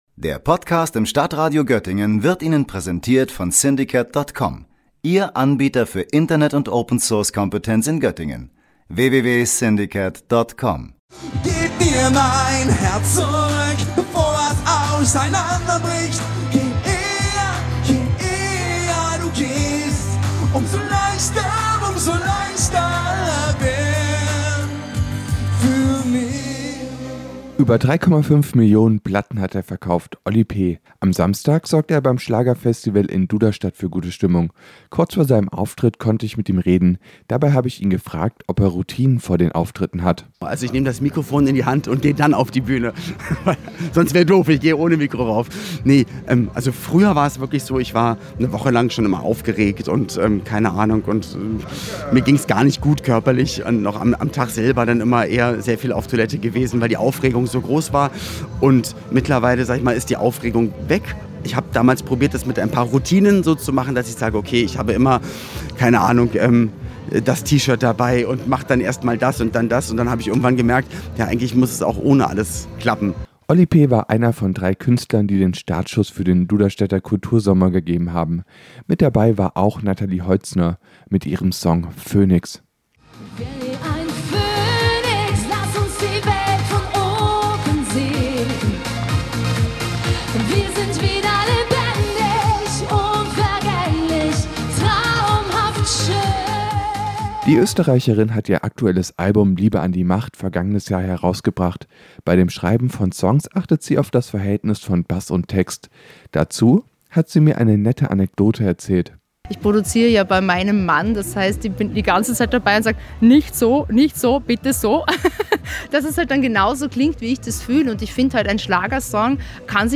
Die erste Sommer-Kultur-Reihe in der Region hat begonnen: Der Duderstädter Kultursommer. Auftaktveranstaltung war das Schlagerfestival.
Oli P. gab beim Auftritt in Duderstadt alles.